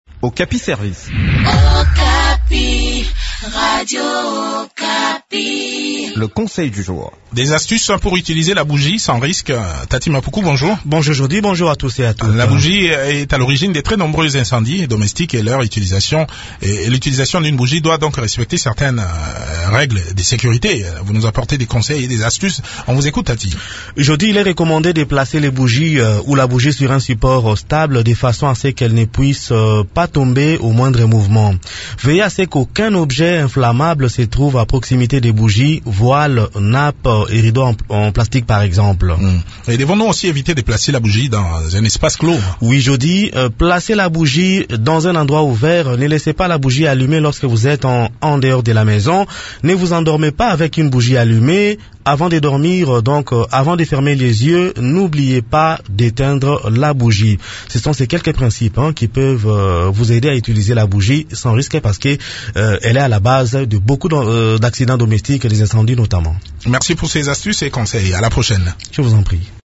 La bougie est à l'origine de très nombreux incendies domestiques et leur utilisation doit donc respecter certaines règles de sécurité. Voici quelques conseils pour utiliser des bougies sans courir aucun risque d'incendie dans cette chronique